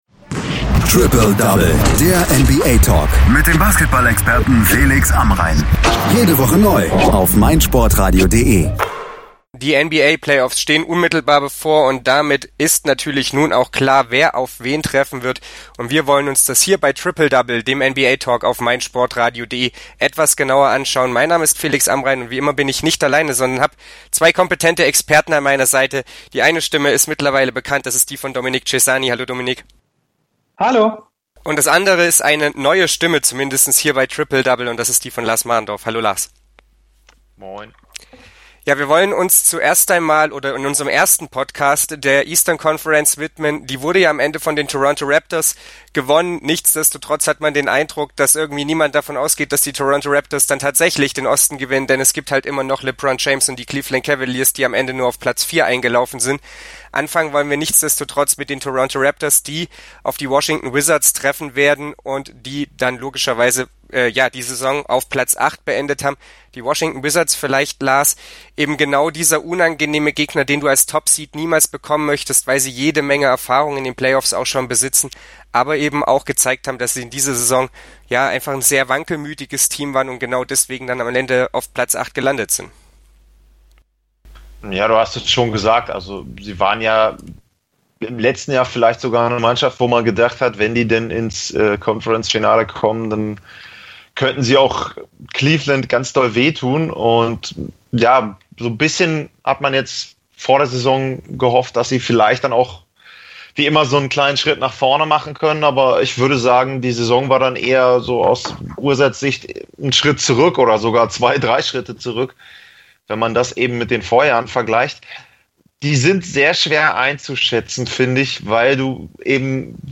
Welche Stärken und Schwächen zeichnen die Teams aus? Welche Spieler werden zum Zünglein an der Waage? Die drei besprechen es in aller Ausführlichkeit bei Triple Double.